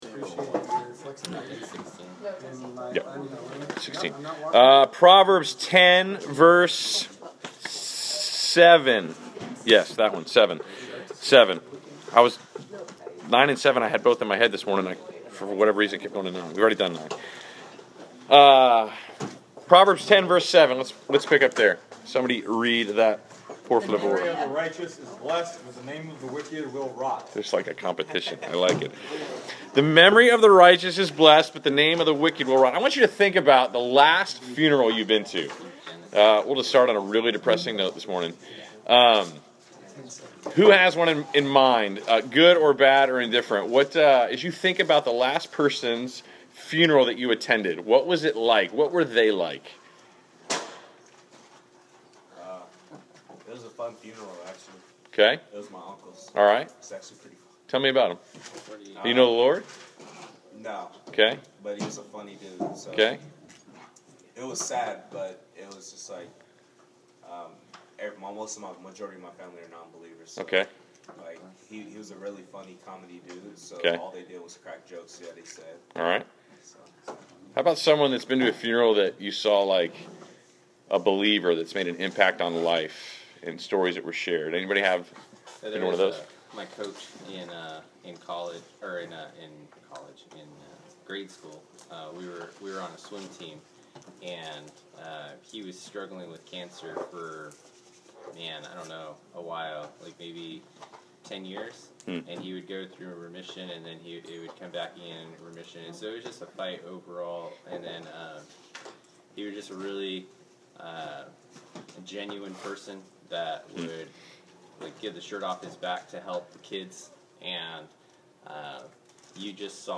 Class Session Audio March 10